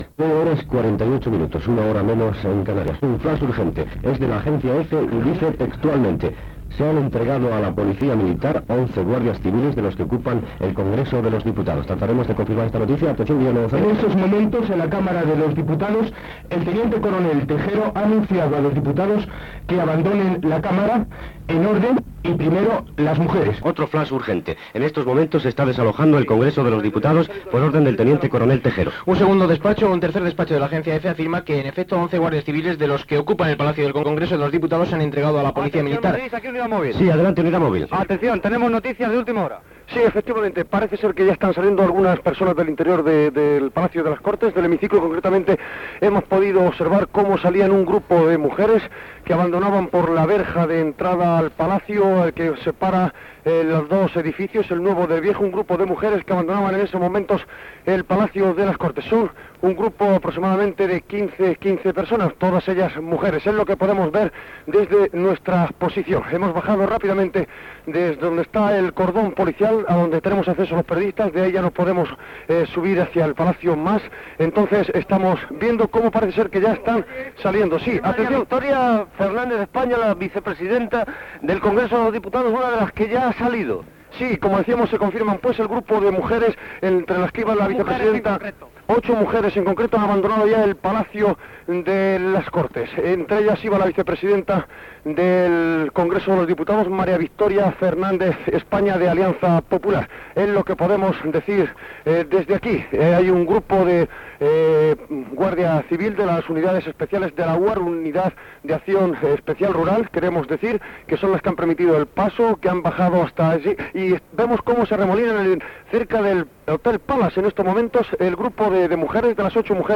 Informatiu
Extret del casset "La SER informa de pleno" publicat per la Cadena SER